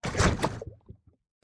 Minecraft Version Minecraft Version 1.21.5 Latest Release | Latest Snapshot 1.21.5 / assets / minecraft / sounds / mob / strider / step_lava6.ogg Compare With Compare With Latest Release | Latest Snapshot
step_lava6.ogg